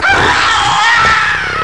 PERSON-Scream+4
Tags: combat